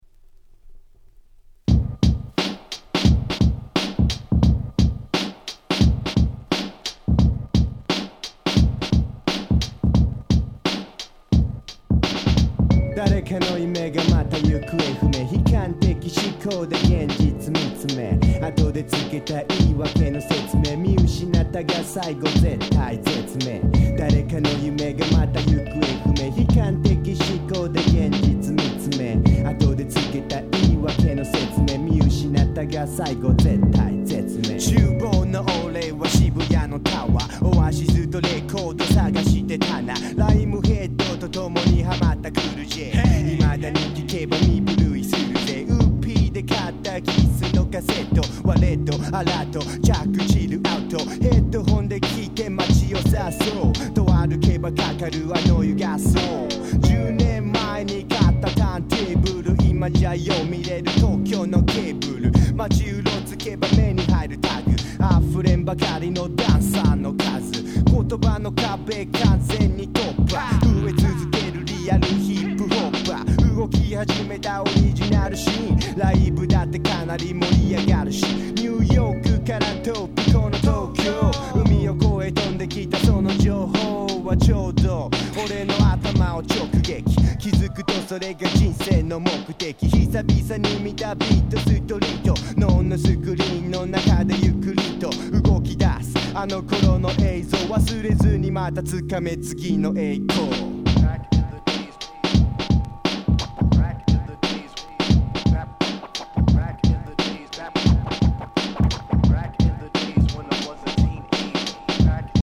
96' Japanese Hip Hop Classics !!
日本語ラップ